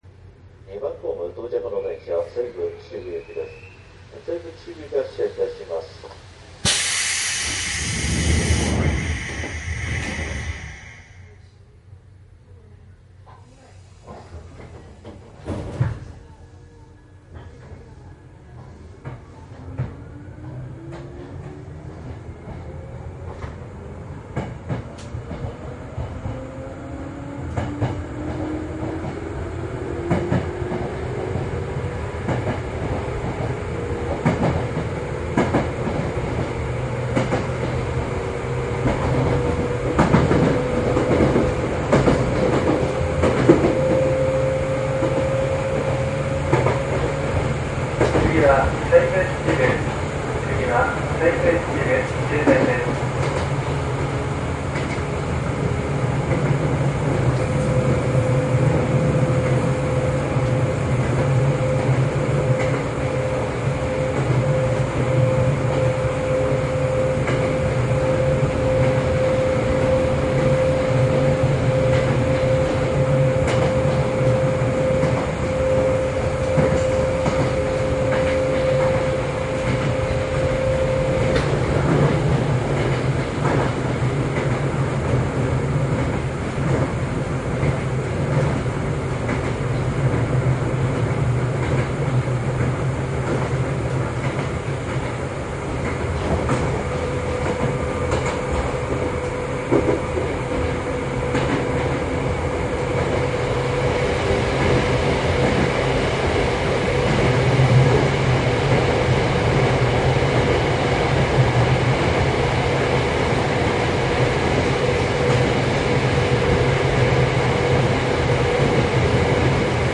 西武秩父線と豊島線で101系を録音した走行音です。
いずれもマイクECM959です。DATかMDの通常SPモードで録音。
実際に乗客が居る車内で録音しています。貸切ではありませんので乗客の会話やが全くないわけではありません。